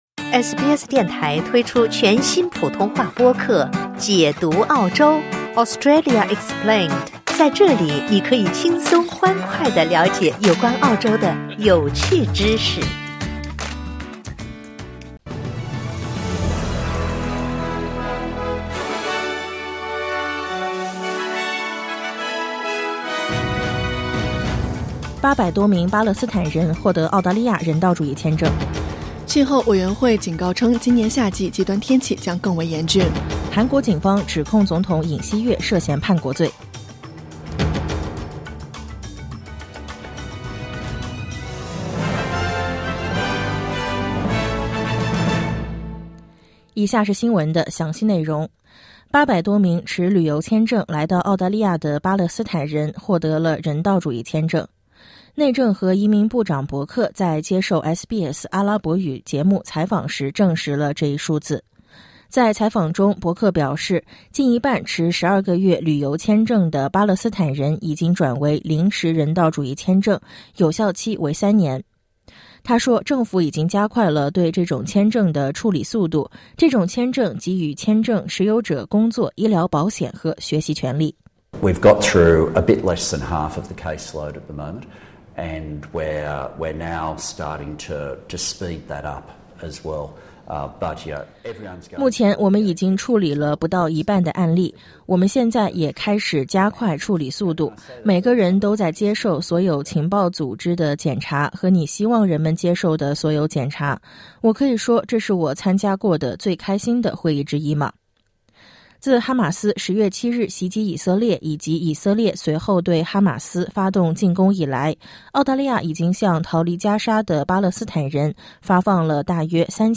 SBS早新闻（2024年12月6日）
SBS Mandarin morning news Source: Getty / Getty Images